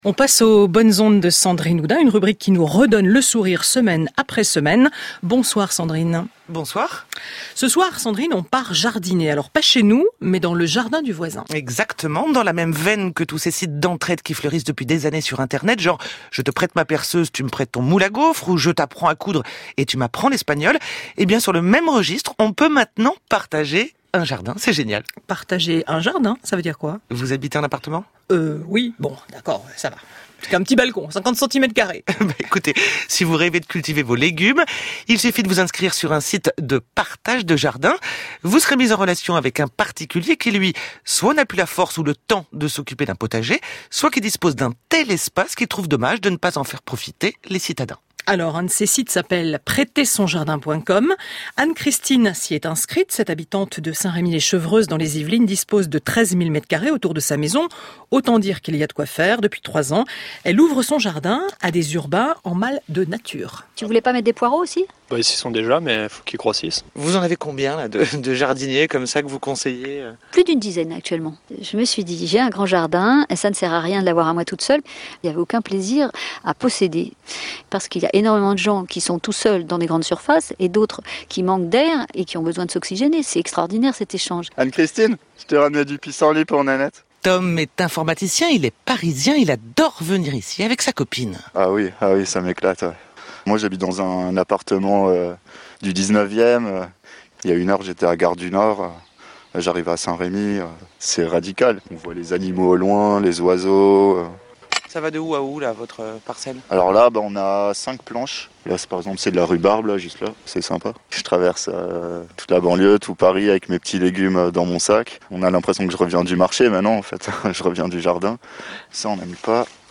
Interview audio sur le prêt de jardin.